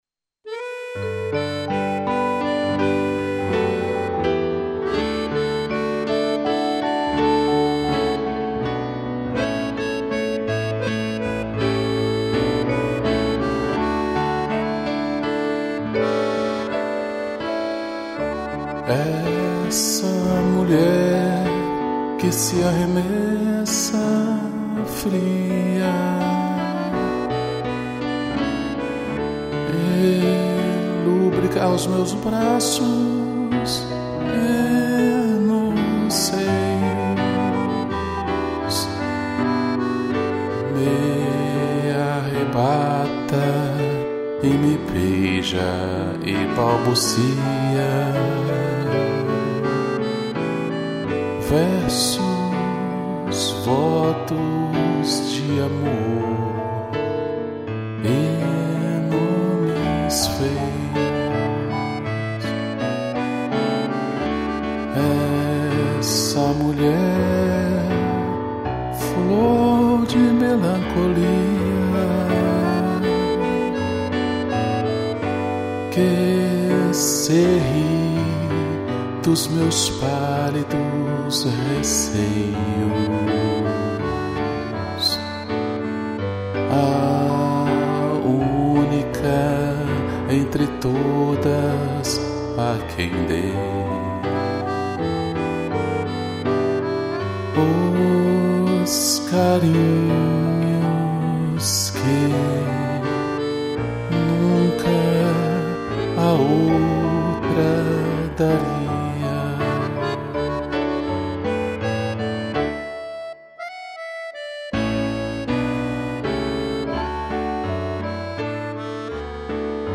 2 pianos e acordeão